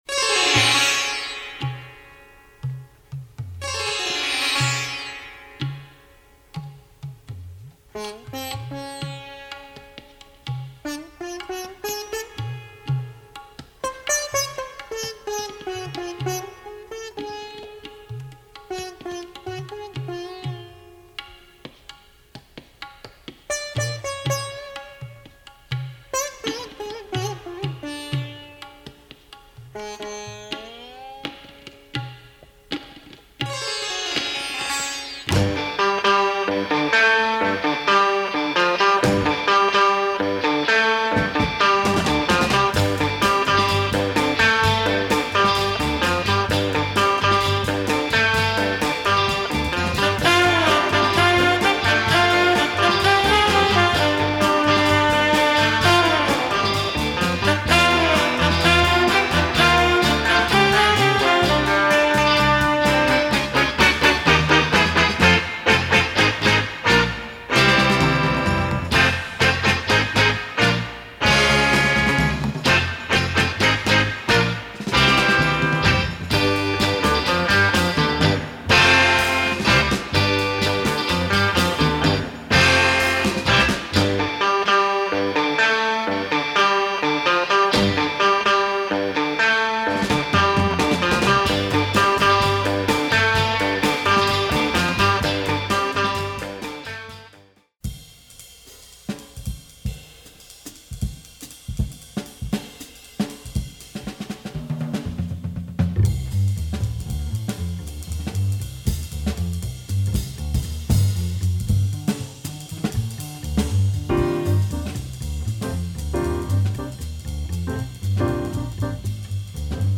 Jazz, pop sitar, soul, really a beautiful soundtrack.